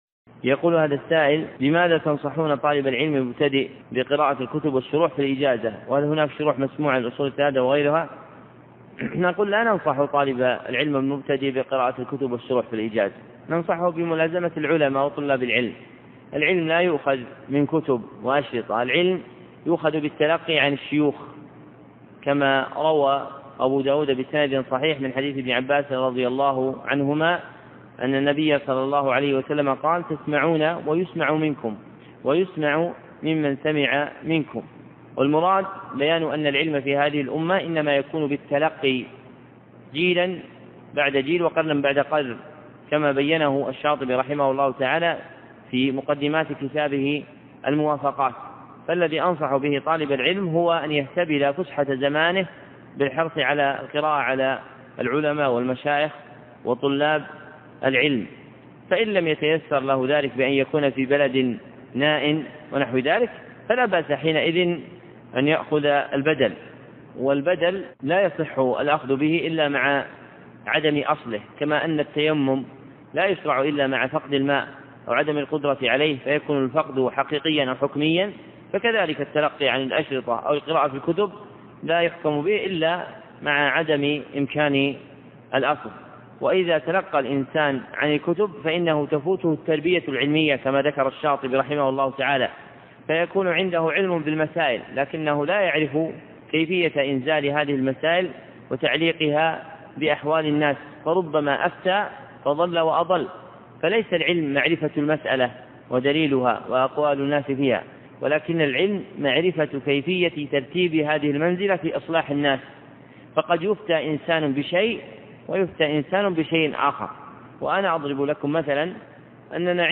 210. 23 - بماذا تنصحون طالب العلم المبتدء خلال الإجازة؟ الدرس الواحد الخامس